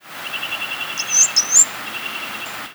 Shiny Cowbird diurnal
Shiny Cowbird diurnal call variation
Flight whistle from perched bird with Northern Mockingbird in the background.